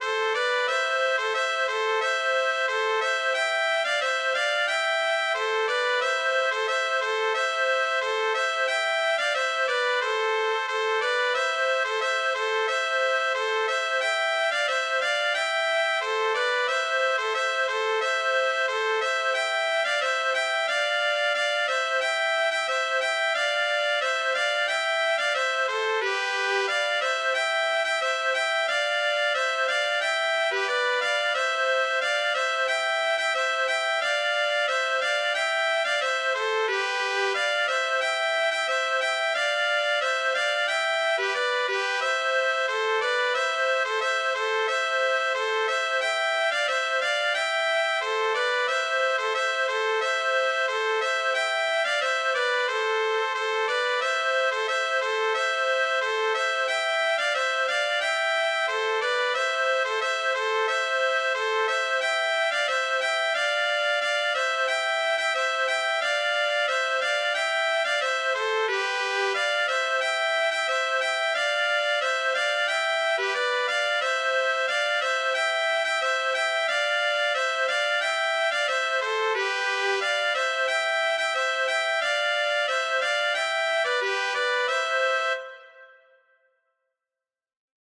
Genre An dro
Instrumente Flöte, Klarinette, Trompete
Tonalität C-Dur
Rythmus 4/4
Tempo ♩=90